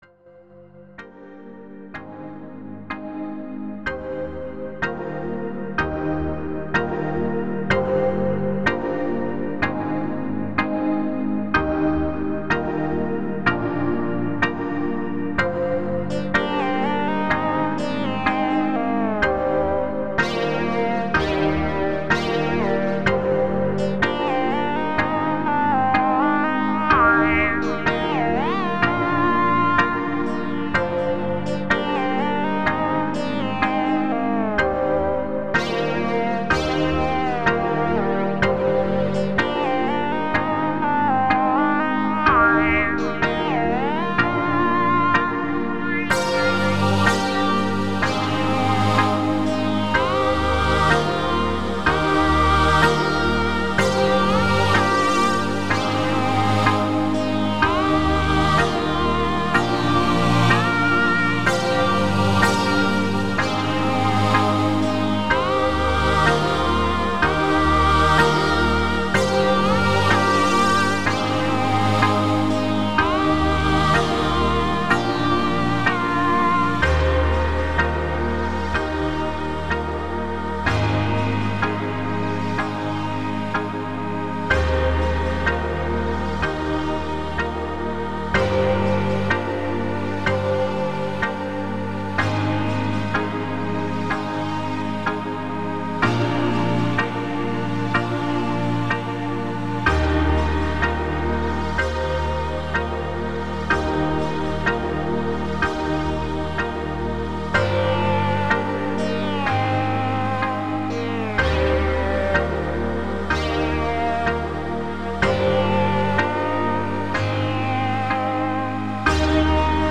• Music has an ending (Doesn't loop)